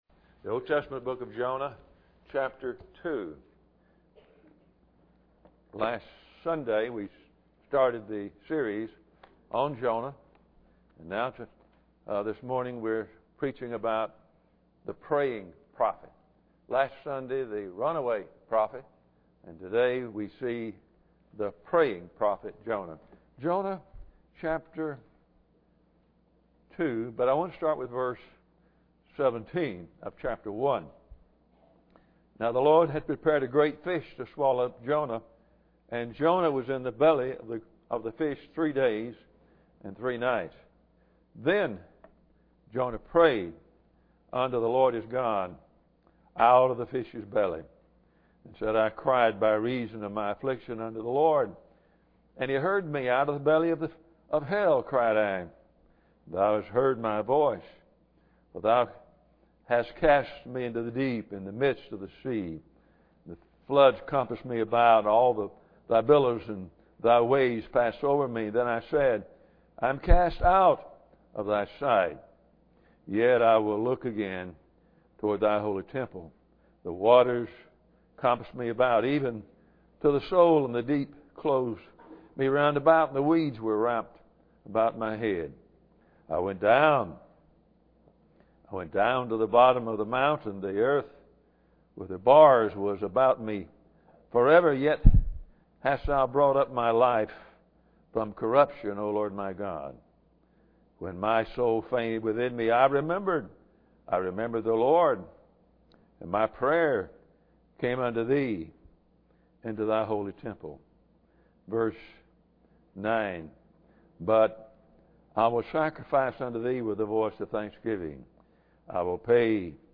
Jonah 1:17-10 Service Type: Sunday Morning Bible Text